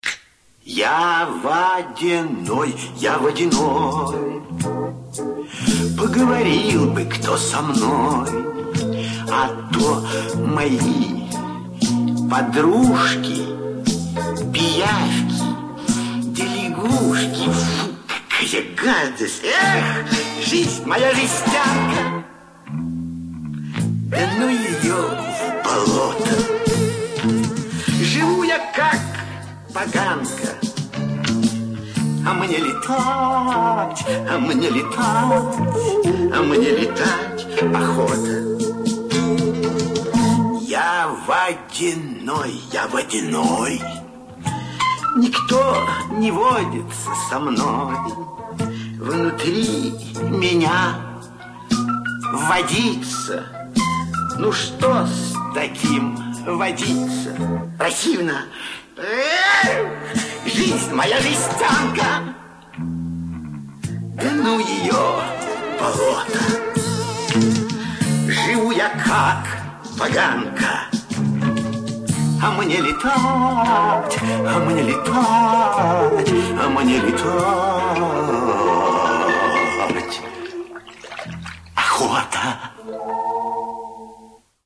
Детские песенки